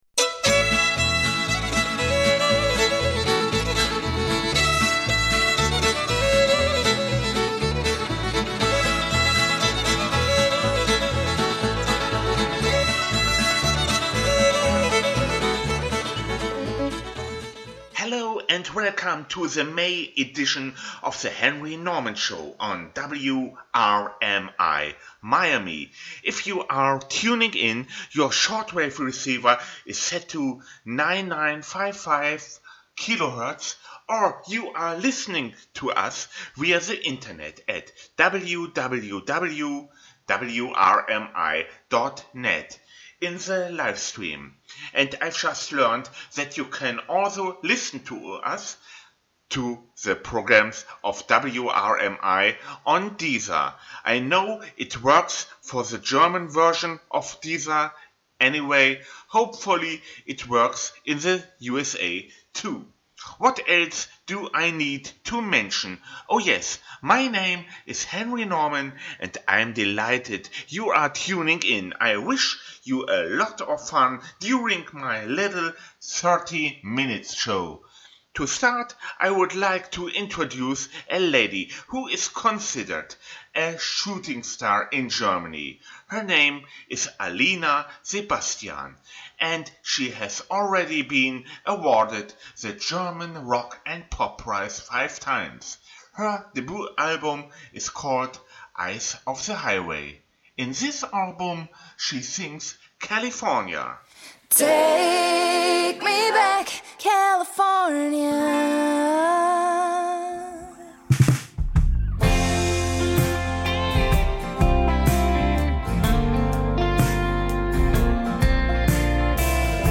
Countrymusicspecial
Produced for WRMI Miami